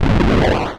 resurrect.wav